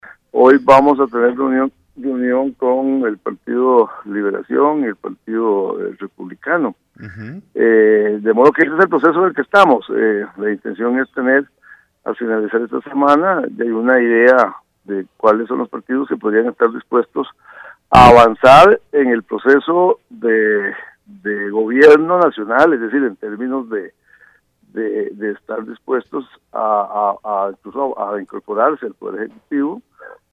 El jefe de fracción del PAC en la próxima Asamblea Legislativa, Víctor Morales Mora, manifestó en la revista informativa «89.1HOY» que ayer se reunieron con la fracción del Frente Amplio y el Partido Integración Nacional para conocer las posiciones de estos.